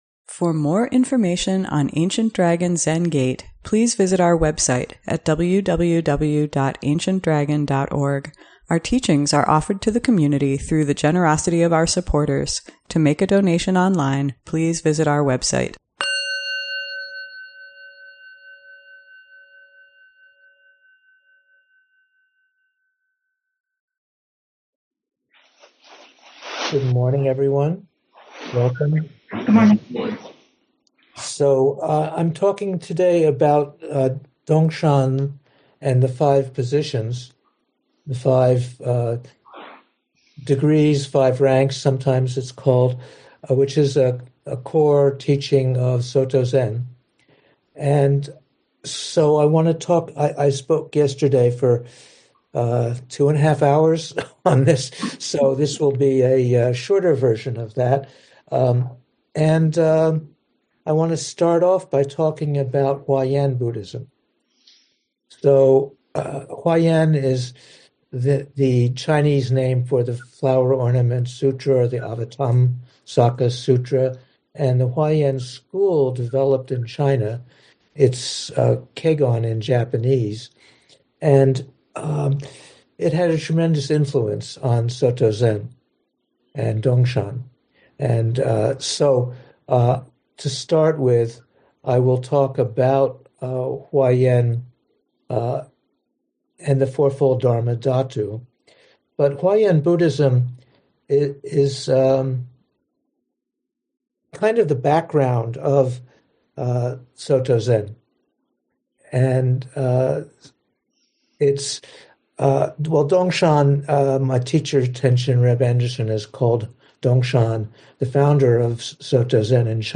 ADZG Sunday Morning Dharma Talk